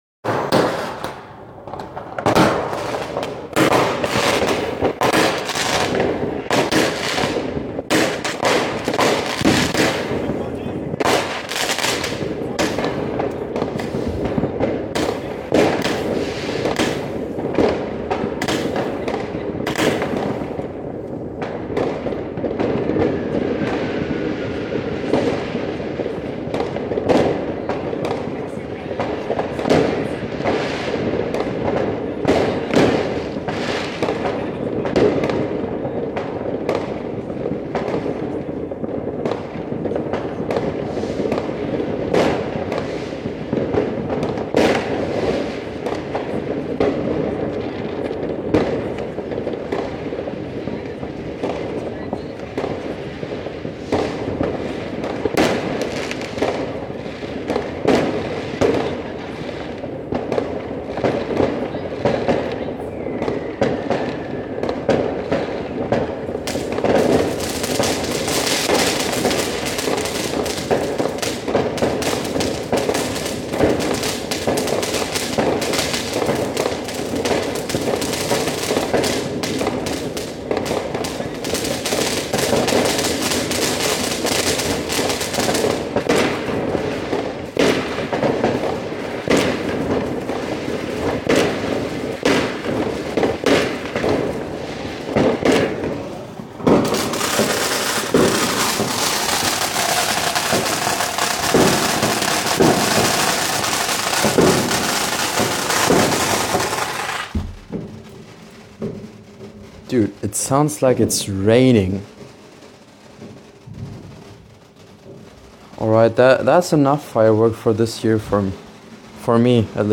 And this is how it basically sounds outside, recorded from my window sill.